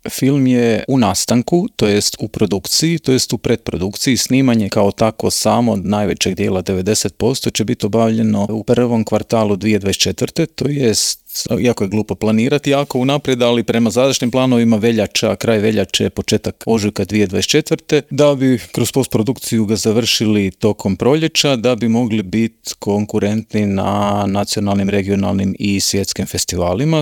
Povodom najave filma, ugostili smo ga u Intervjuu Media servisa, te ga za početak pitali u kojoj je fazi film naziva BETA.